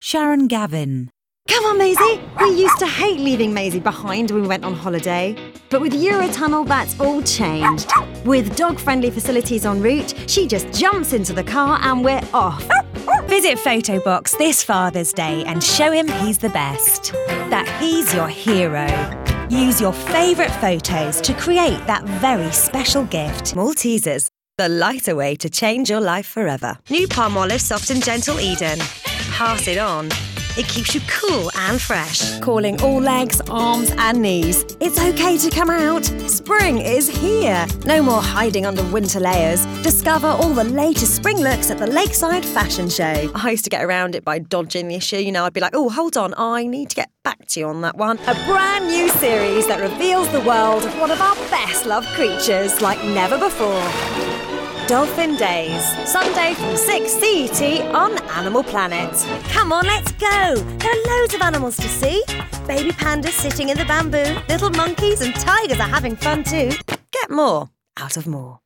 Authentic British Professional commercial samples
English - British and European, English - Transatlantic